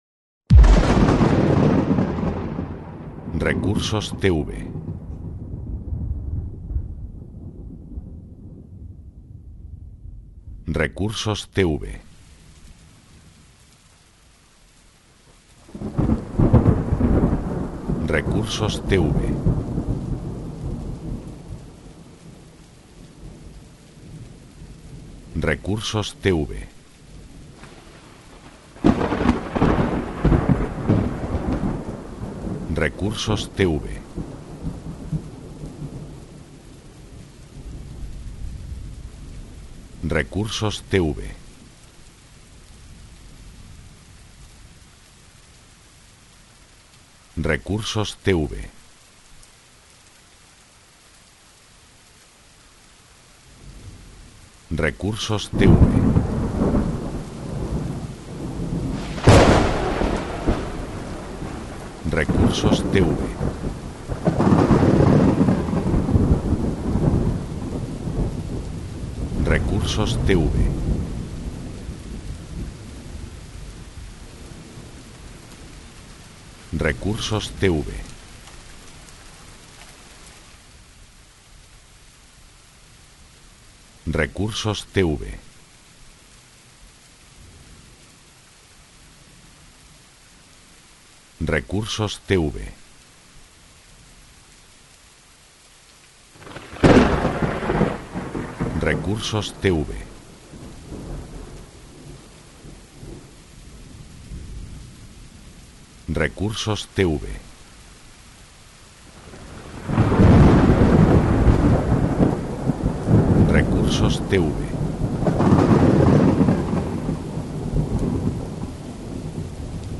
Efecto sonido truenos y lluvia en el silencio de la noche.